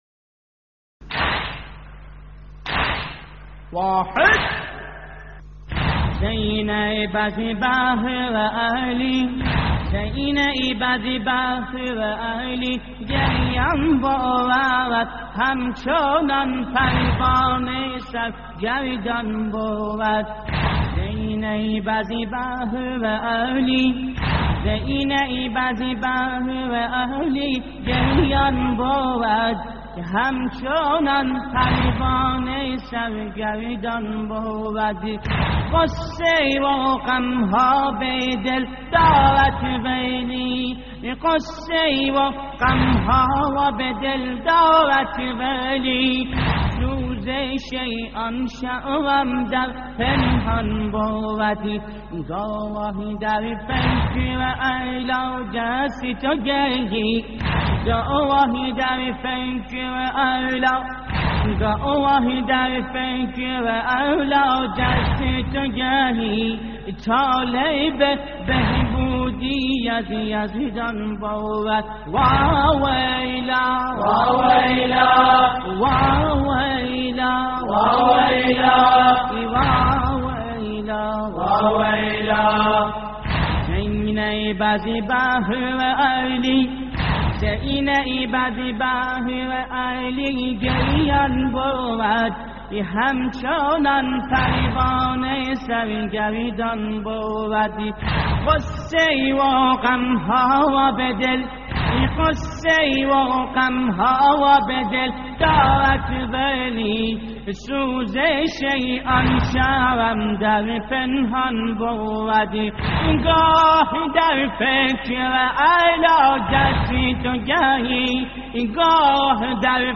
استديو «وغابت الشمس» فارسي لحفظ الملف في مجلد خاص اضغط بالزر الأيمن هنا ثم اختر